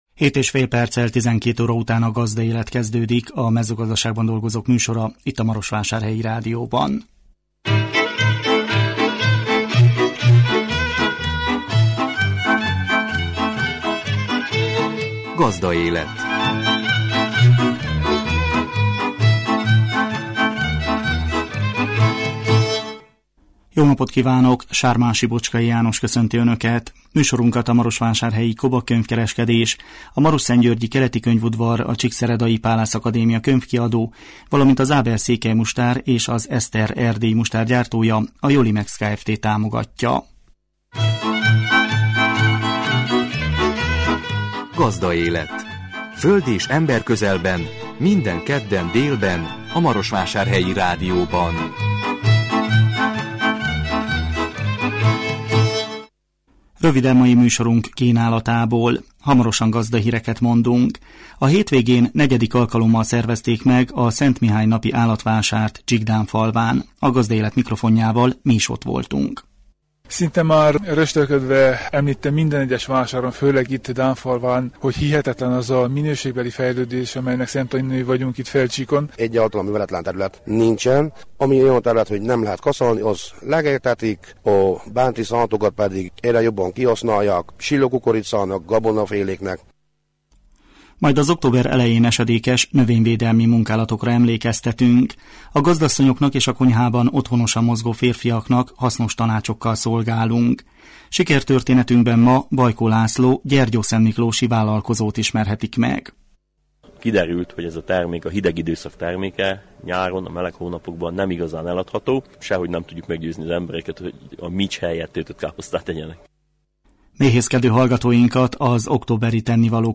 A hétvégén 4. alkalommal szervezték meg a Szent Mihály napi állatvásárt Csíkdánfalván. A Gazdaélet mikrofonjával mi is ott jártunk.